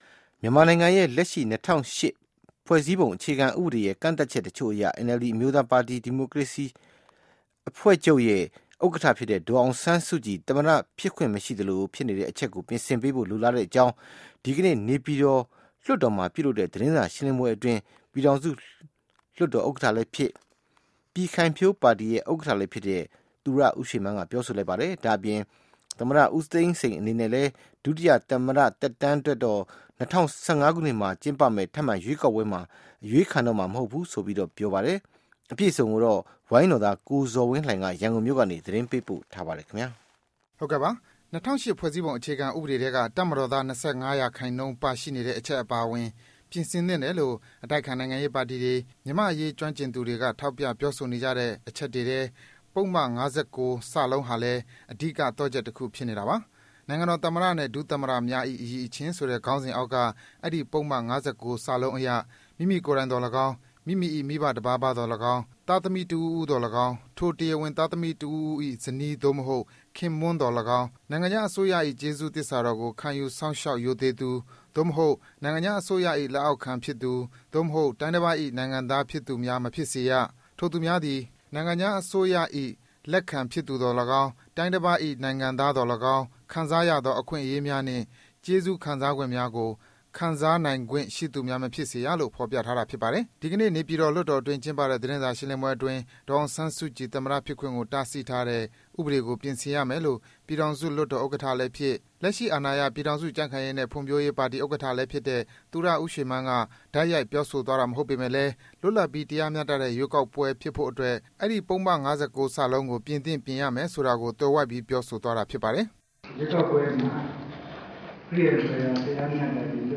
သူရဦးရွှေမန်း သတင်းစာရှင်းလင်းပွဲ